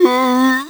c_zombim4_hit3.wav